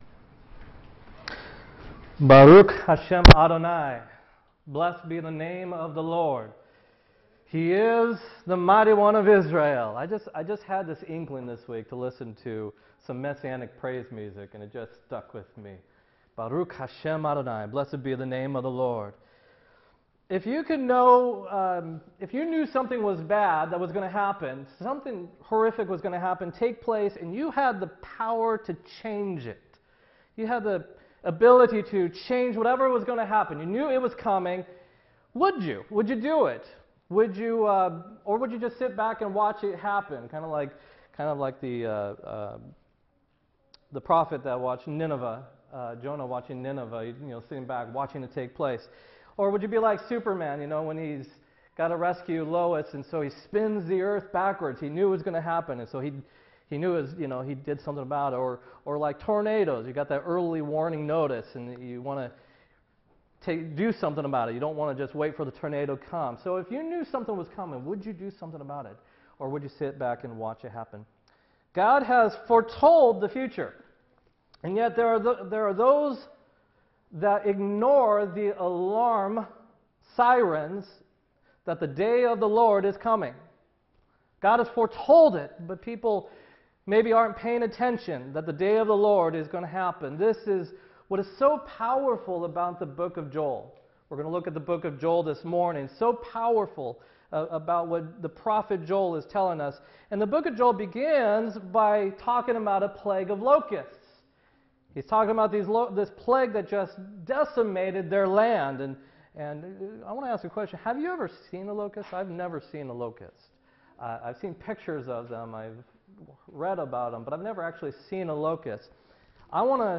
12-17-16 sermon